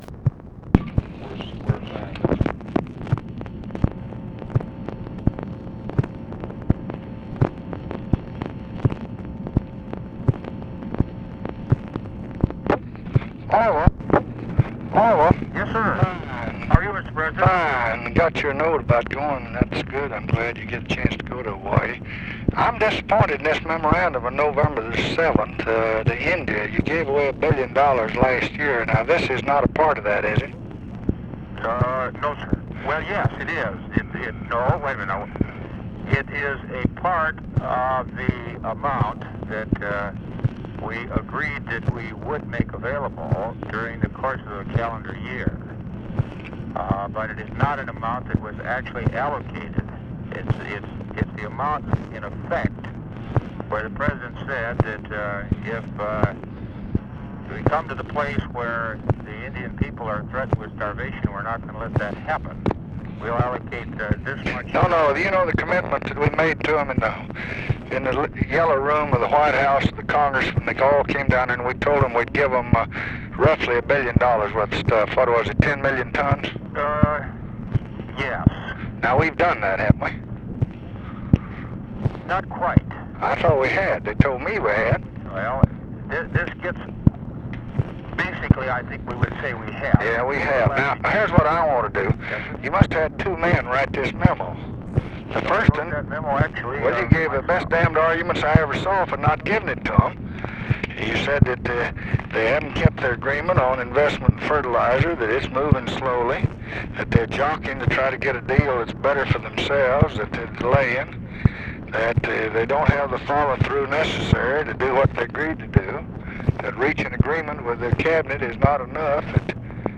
Conversation with ORVILLE FREEMAN, November 10, 1966
Secret White House Tapes